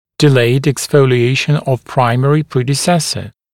[dɪ’leɪd eksˌfəulɪ’eɪʃn əv ‘praɪmərɪ ‘priːdɪˌsesə][ди’лэйд эксˌфоули’эйшн ов ‘праймэри ‘при:диˌсэсэ]задержка выпадения молочного зуба